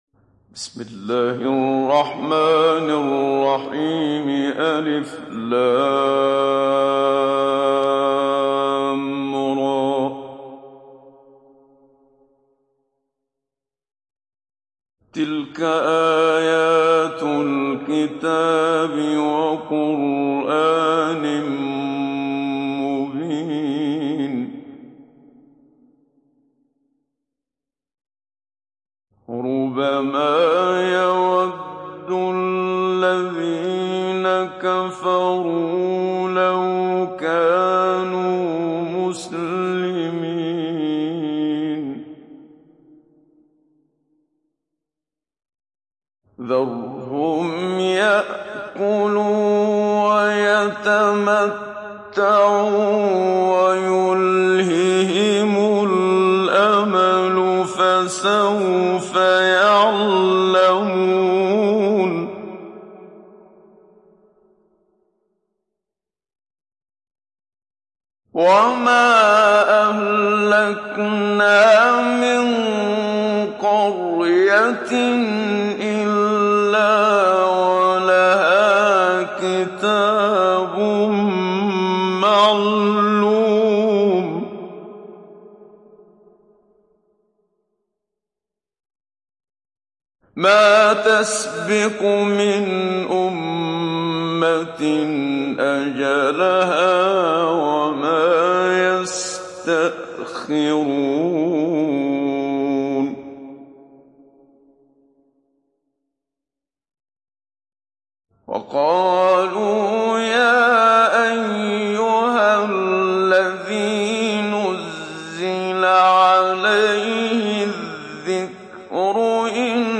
Download Surah Al Hijr Muhammad Siddiq Minshawi Mujawwad